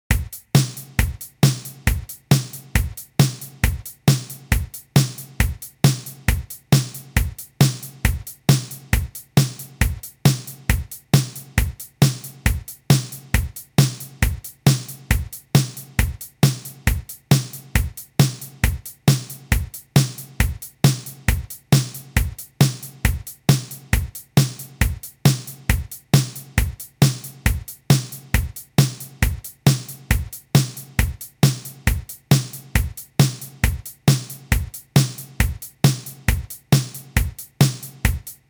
ogg(L) ずっと同じ調子
安っぽいドラムの単調な繰り返し。